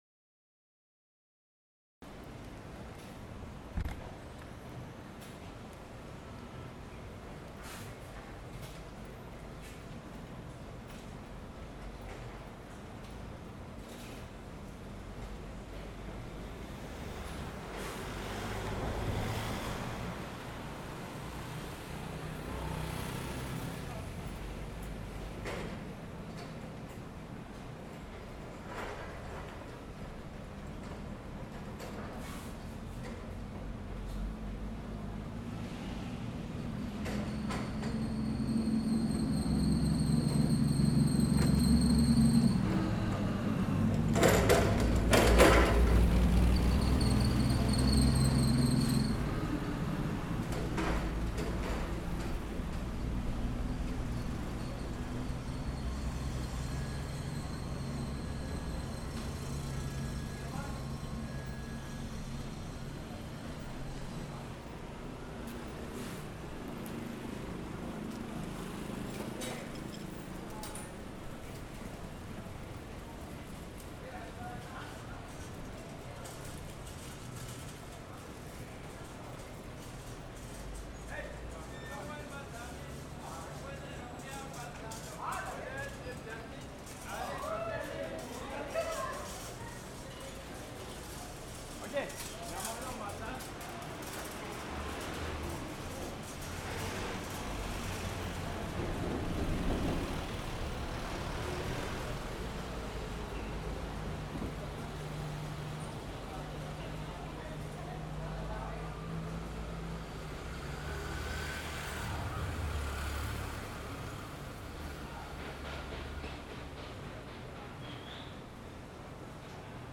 Carrera 3 entre calles 19 y 20 Barrio San Nicolás (Mediodía)
De manera especial vale señalar la presencia del sonido de las campana a las 6:00 pm, el cual sumado a las voces de las personas que salen de trabajar, indican el fin de la jornada laboral. Vale la pena destacar que, a pesar del tráfico vehicular denso en esta franja, no predominó el sonido de bocinas.